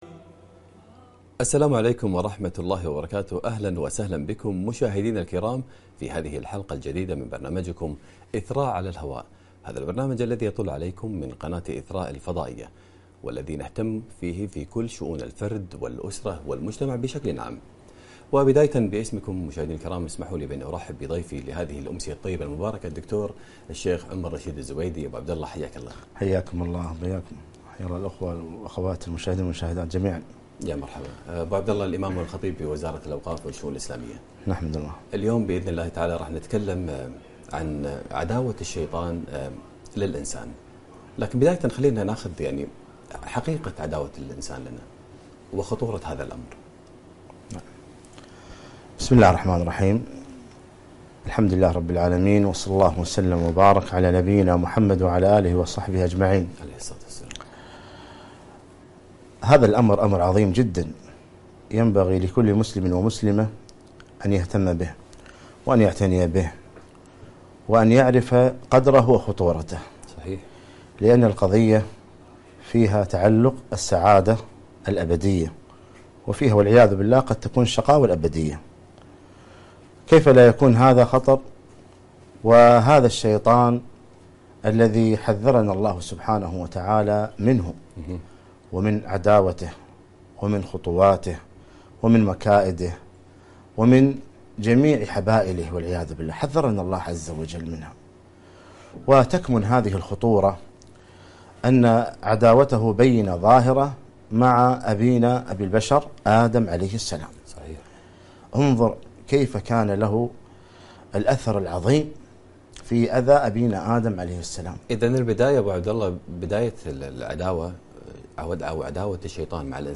عداوة الشيطان للإنسان لقاء عبر قناة إثراء الفضائية